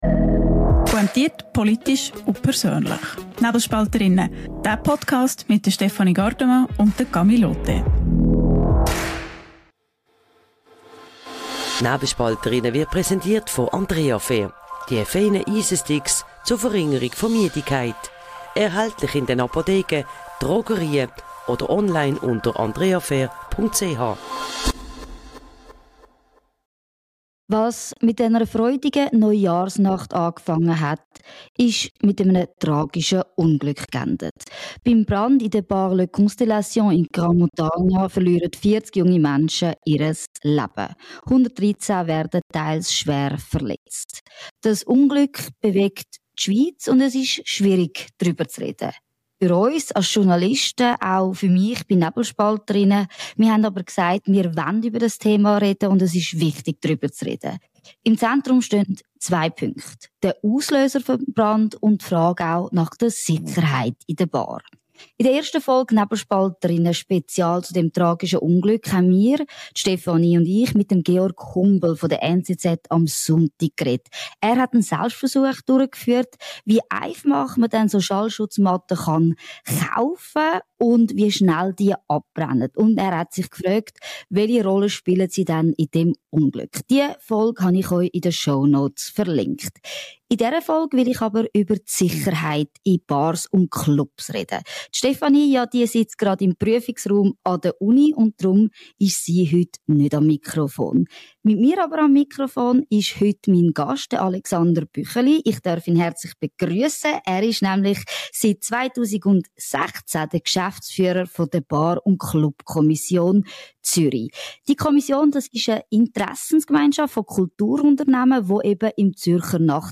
Deshalb setzt seine Organisation auf Sicherheitskurse für Bars und Clubs, inklusive Übungen mit Feuerlöschern. Ein Gespräch über Versäumnisse, Zuständigkeiten und die Konsequenzen für eine ganze Branche.